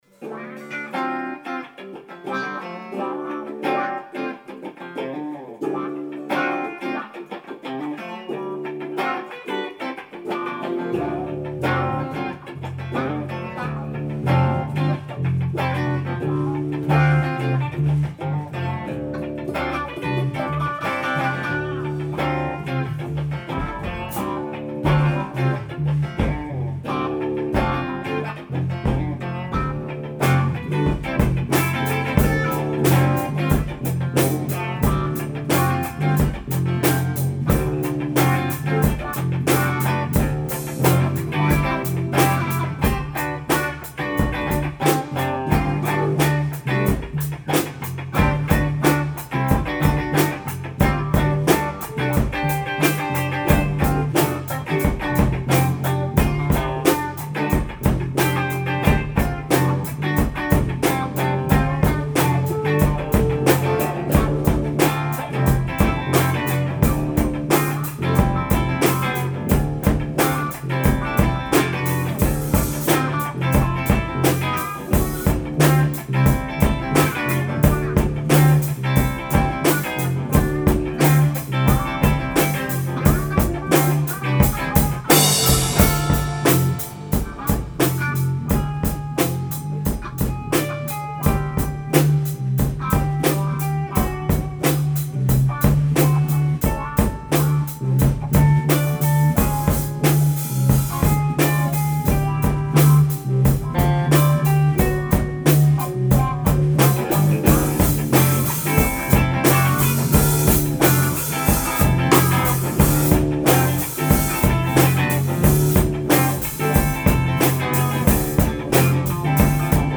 Tempo: 86 bpm / Date: 17.03.2012